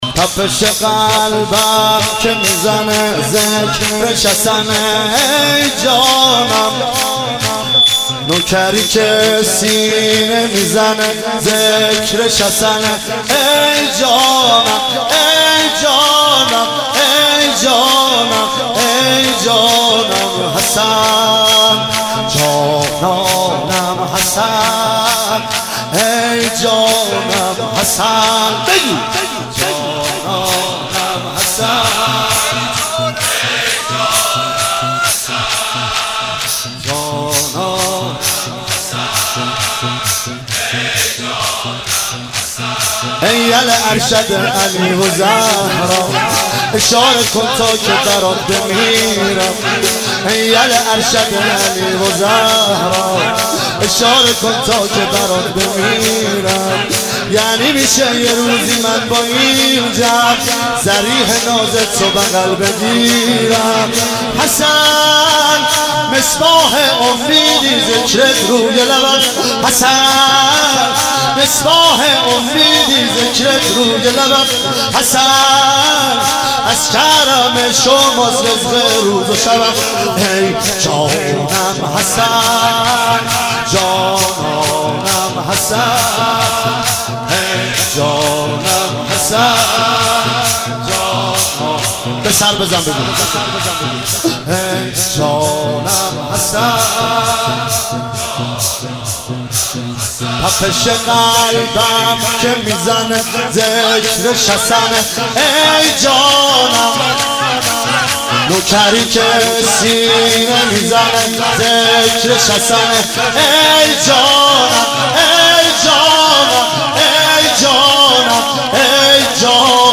شب پنجم محرم 97 - شور - تپش قلبم که میزنه ذکرش حسن